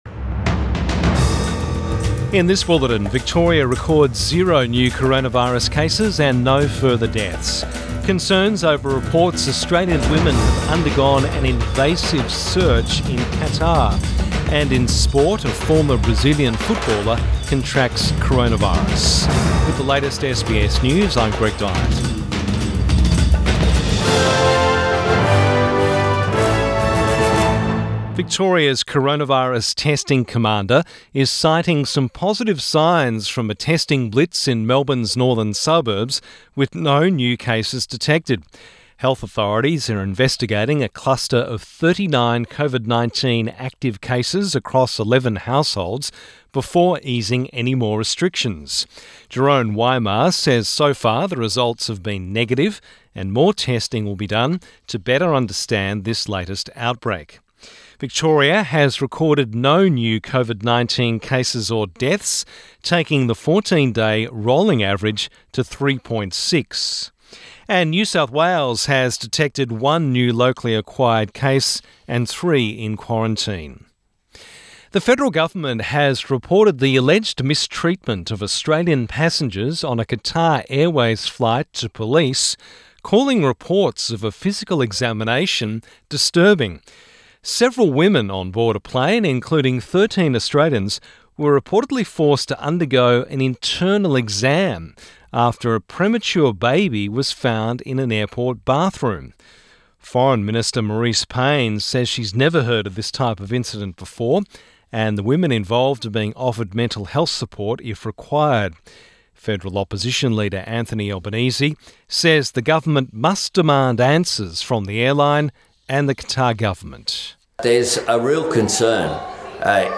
Midday bulletin 26 October 2020